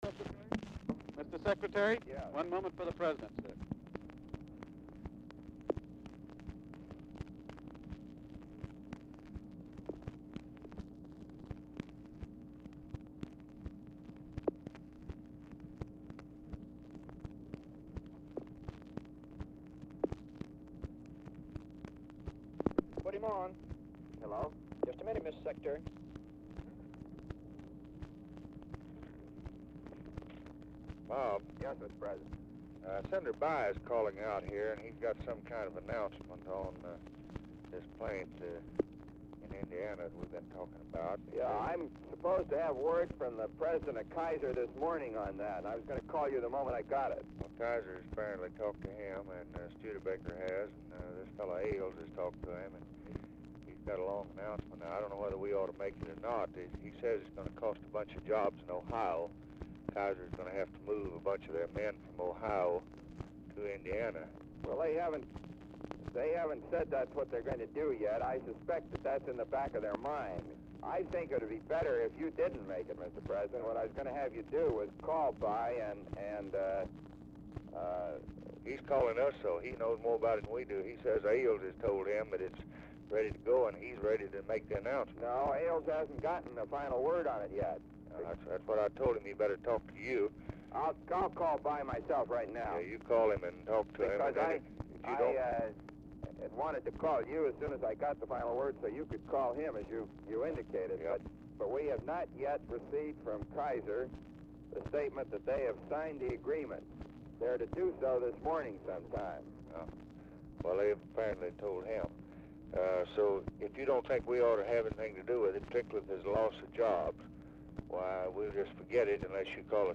Telephone conversation # 2152, sound recording, LBJ and ROBERT MCNAMARA, 2/21/1964, 7:45AM | Discover LBJ
Format Dictation belt
Specific Item Type Telephone conversation Subject Business Communist Countries Congressional Relations Defense Labor Latin America Press Relations Procurement And Disposal